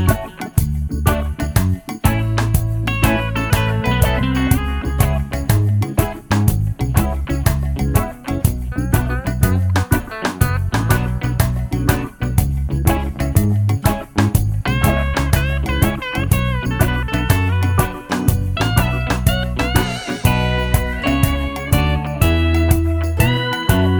no Backing Vocals Reggae 3:59 Buy £1.50